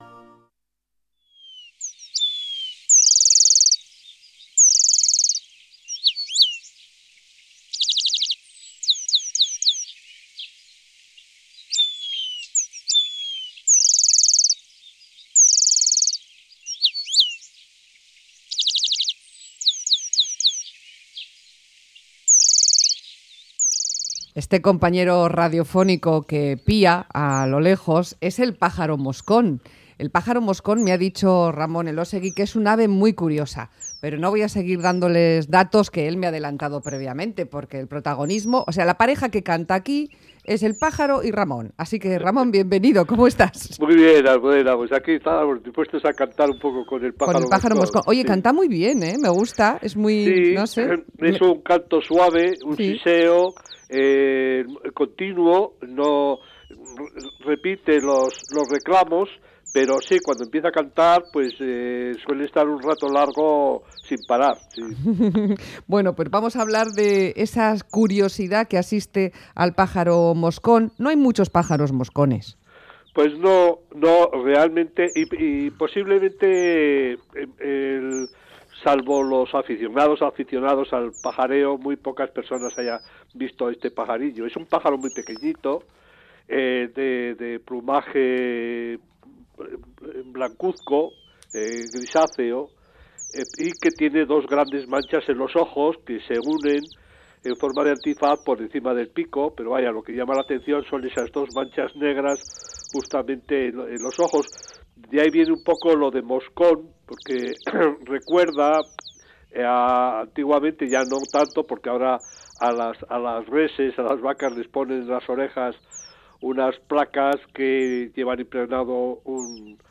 El pájaro mosco o dilindaria o Remiz pendulinusa sisea sin descanso